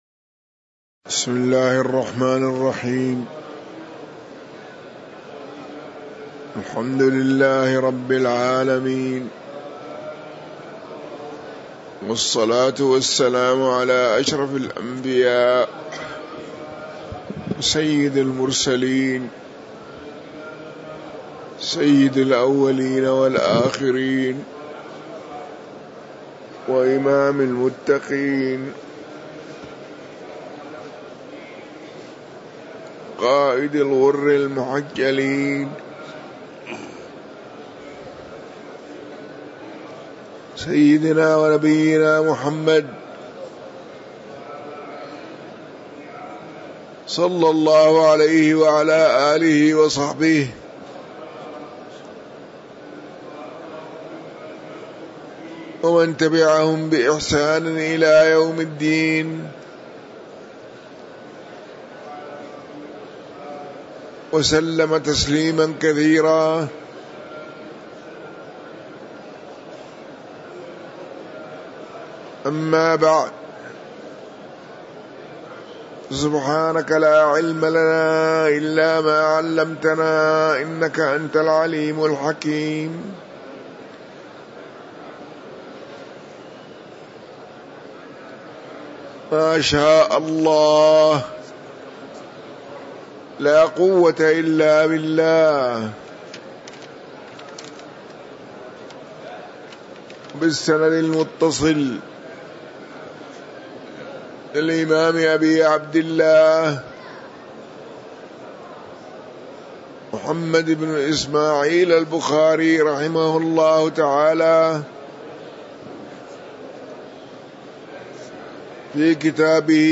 تاريخ النشر ٧ رمضان ١٤٤٤ هـ المكان: المسجد النبوي الشيخ